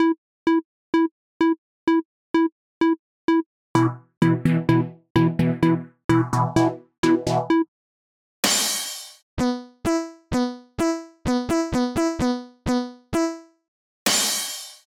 途中で入るドンって音が転んだ時のシーンなどにぴったりです！